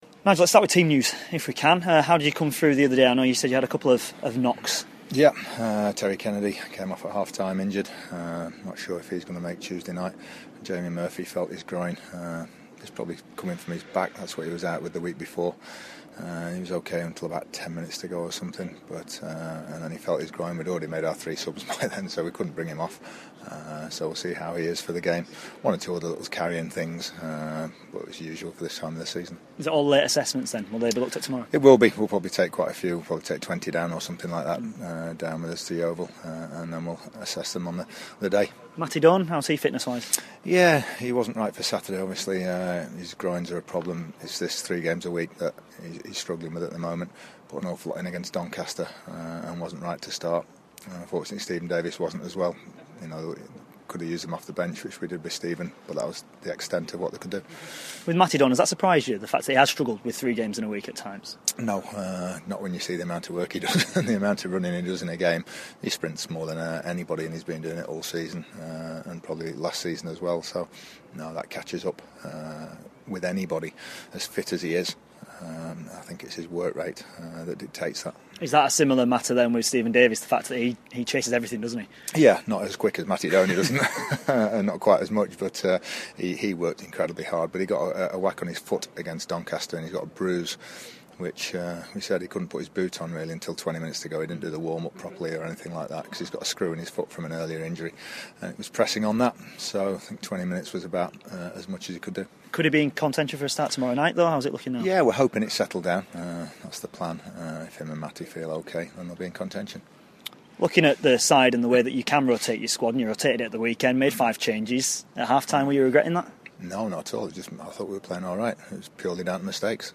INTERVIEW: Sheffield United Manager Nigel Clough ahead of the trip to Yeovil.